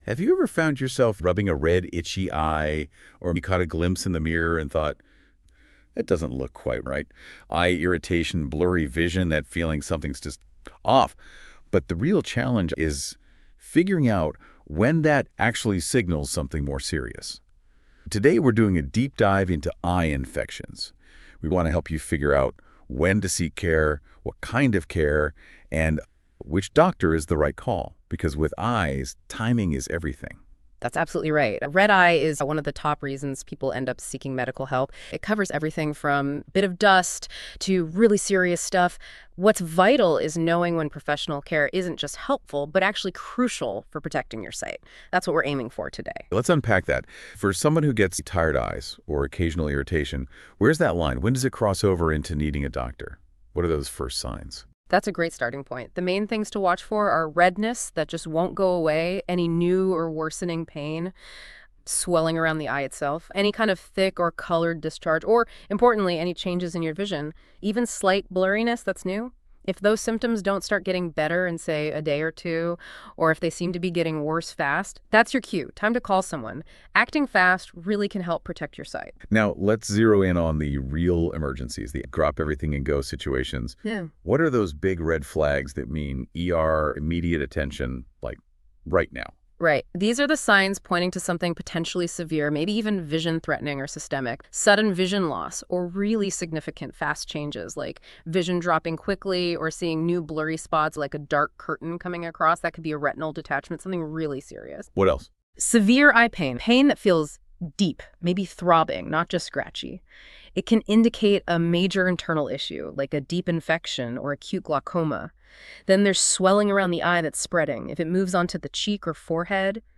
Listen to a discussion on when to see a doctor for an eye infection.